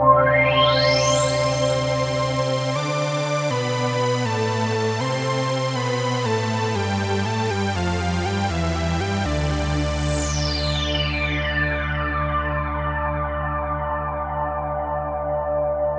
Synth 34.wav